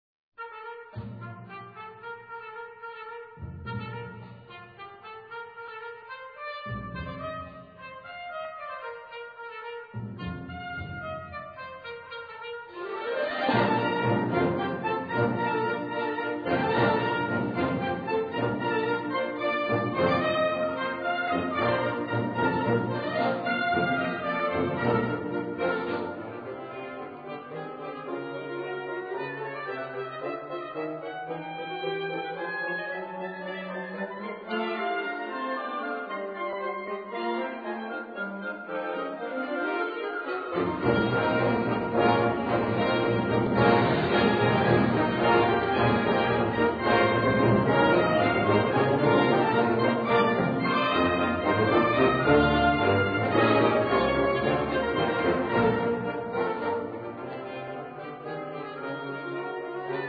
Gattung: Zeitgenössische Originalmusik
Besetzung: Blasorchester